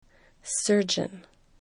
surgeon   sərən